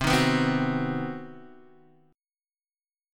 CmM7b5 chord